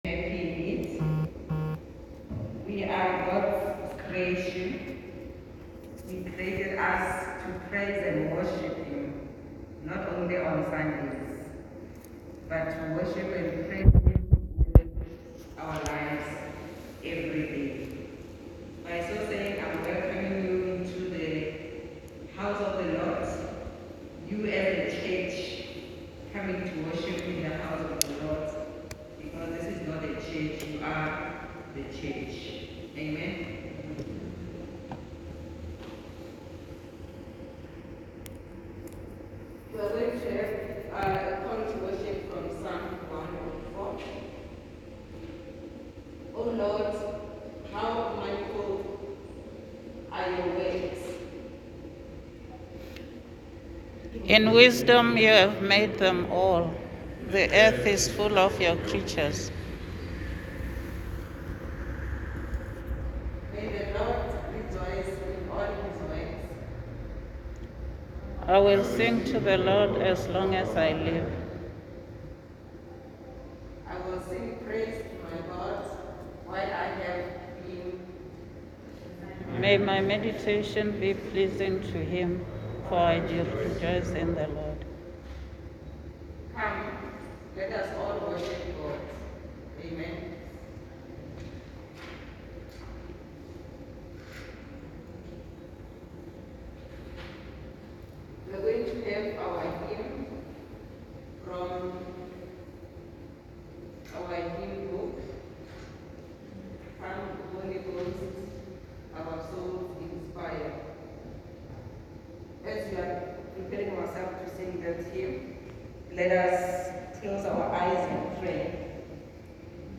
Service video St Columba's Hatfield Pentecost Service 23 May 2021 .m4aDownload